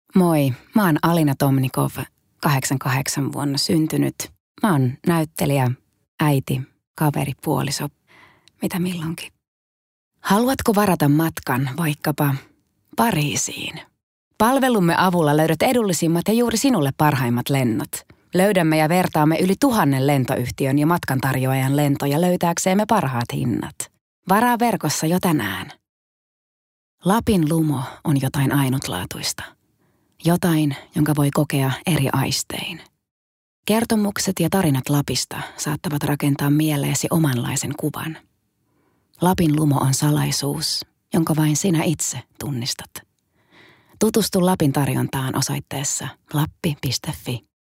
Voice color: neutral